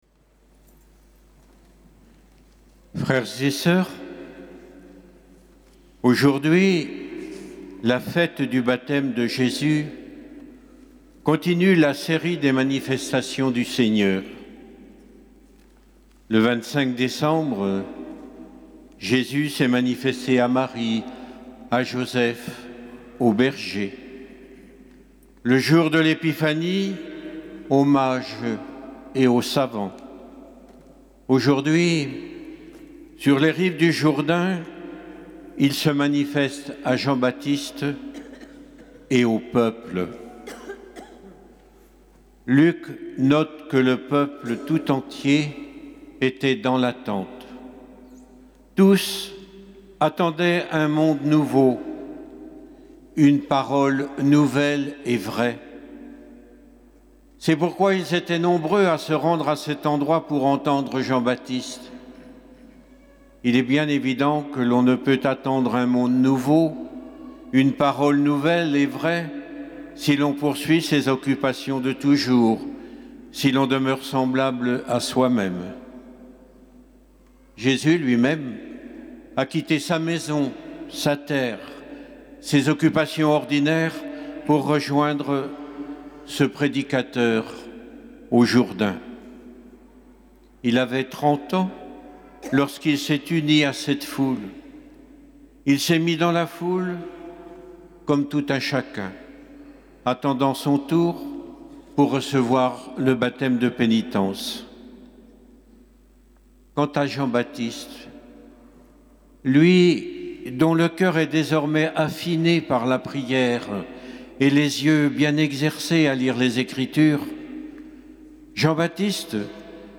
Homélie de Mgr Yves Le Saux